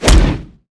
WAV · 31 KB · 單聲道 (1ch)